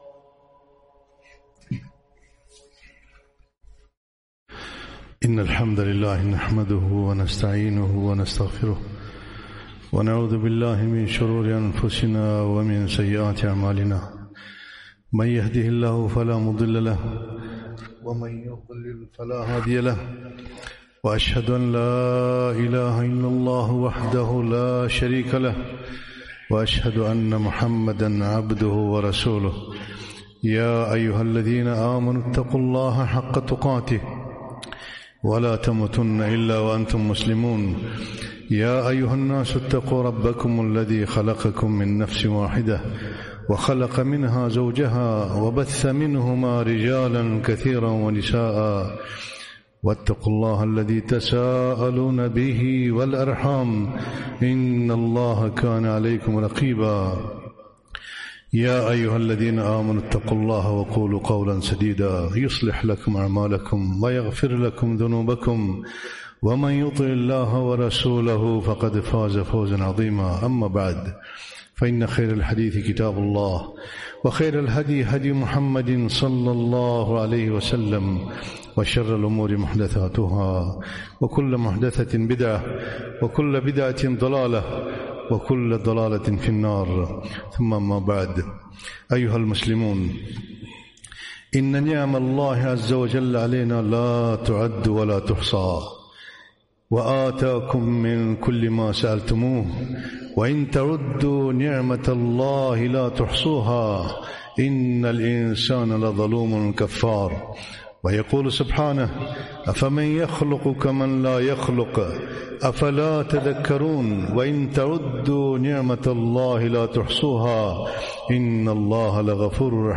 خطبة - غض البصر